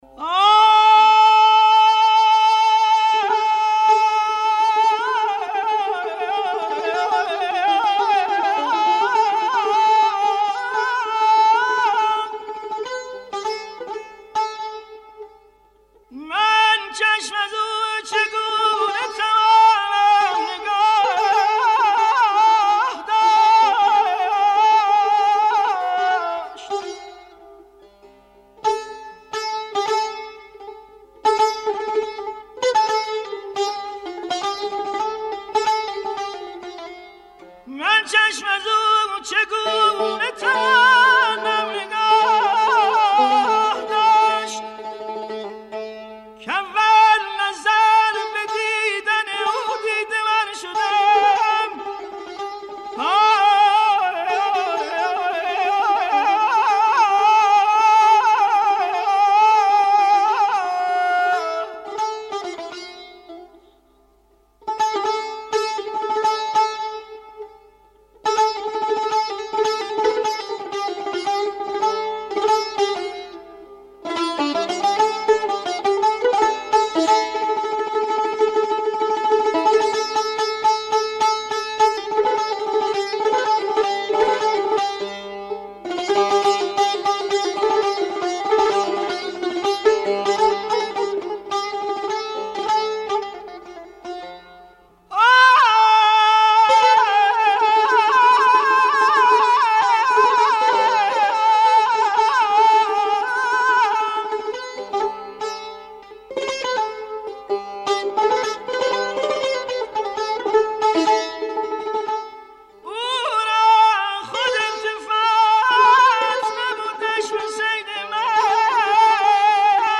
آواز چهارگاه
آواز خوانی موسیقی